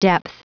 Prononciation du mot depth en anglais (fichier audio)
Prononciation du mot : depth